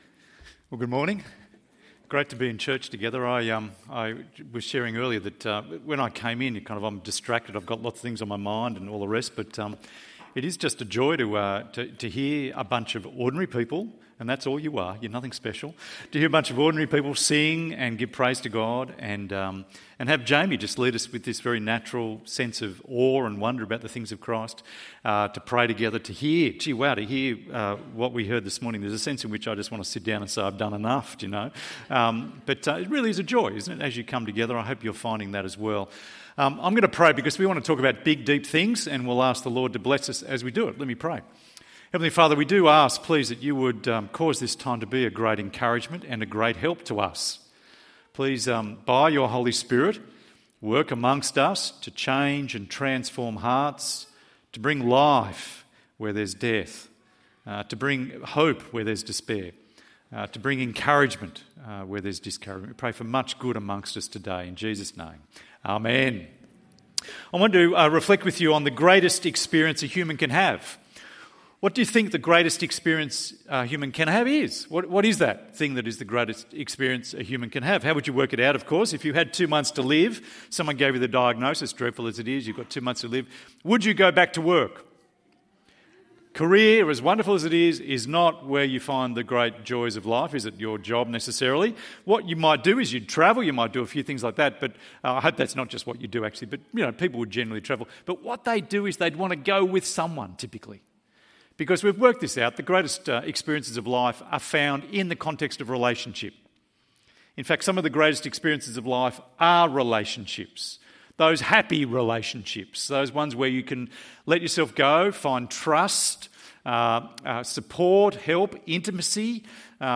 God dwells among his people (Tabernacle - God in our midst) ~ EV Church Sermons Podcast